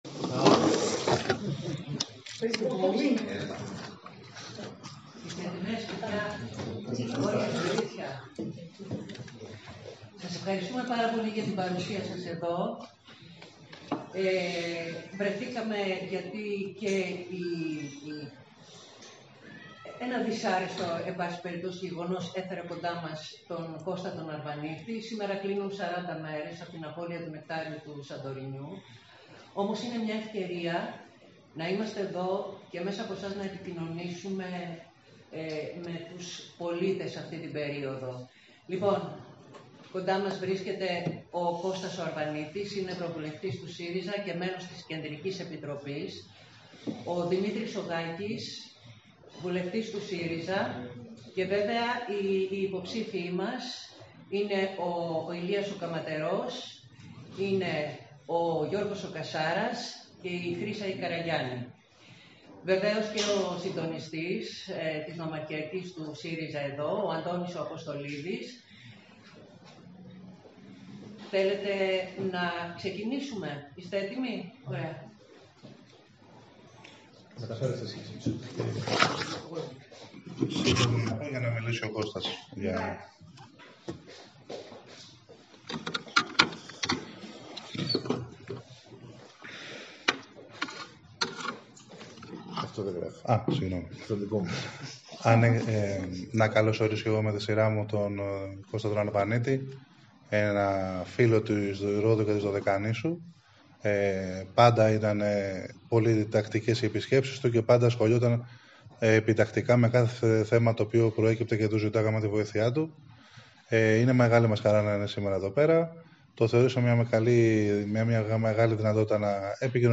Συνέντευξη τύπου δόθηκε σήμερα από στελέχη του ΣΥΡΙΖΑ και κυρίως από τον ευρωβουλευτή κ. Κώστα Αρβανίτη στο δήμο Ρόδου όπως είχε ανακοινωθεί.
ΣΥΝΈΝΤΕΥΞΗ-ΣΥΡΙΖΑ.mp3